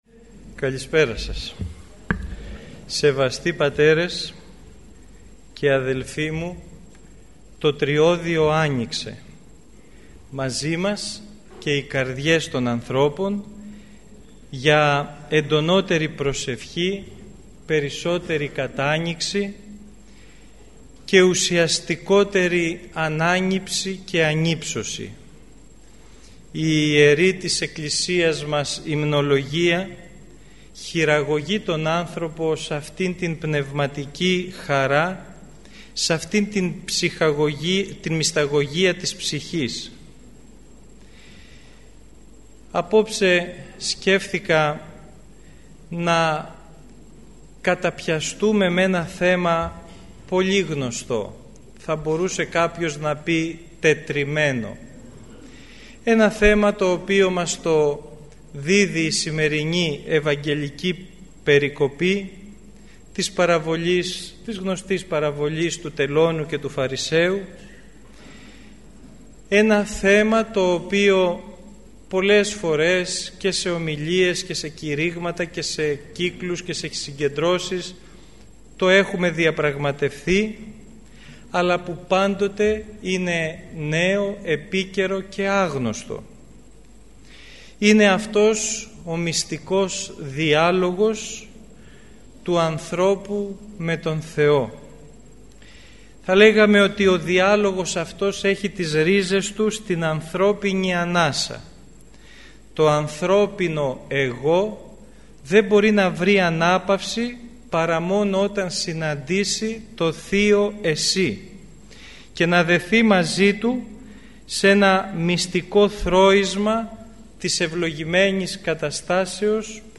Η ομιλία αυτή “πραγματοποιήθηκε” στην αίθουσα της Χριστιανικής Ενώσεως Αγρινίου.